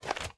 High Quality Footsteps
STEPS Dirt, Walk 28.wav